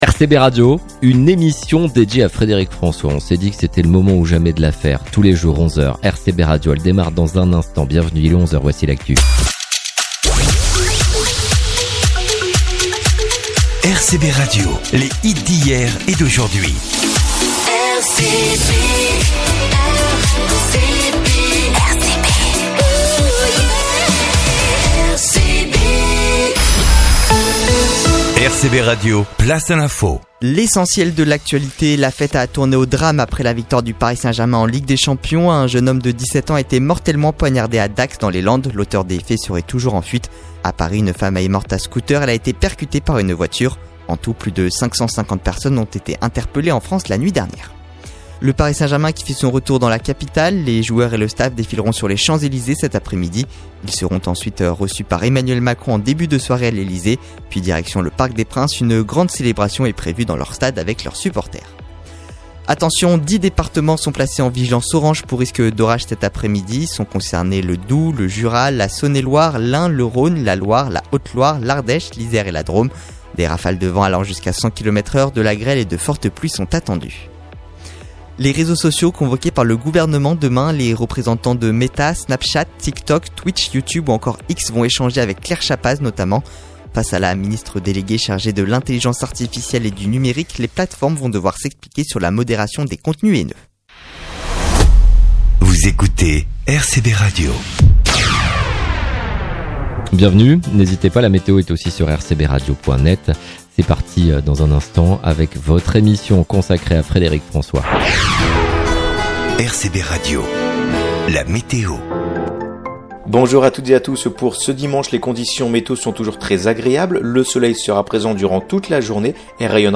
L'emission en podcast de Frederic François du 1 juin 2025 avec son interview